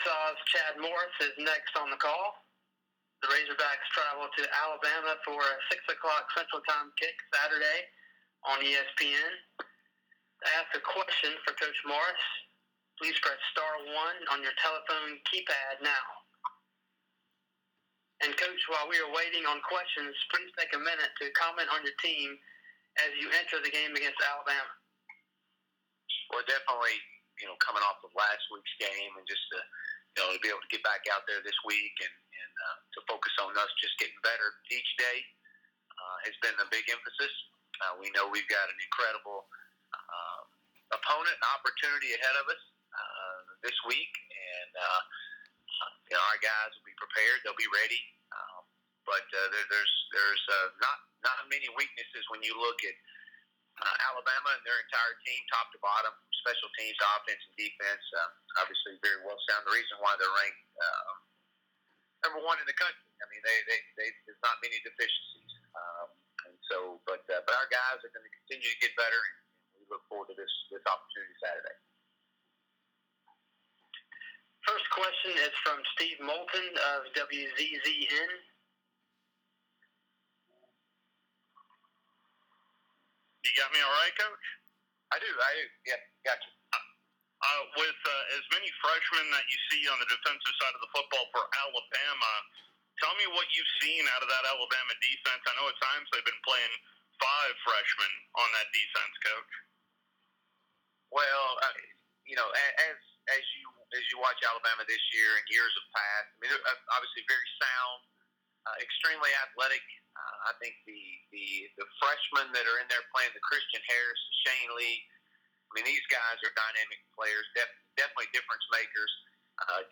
Chad Morris on SEC Teleconference - Week 9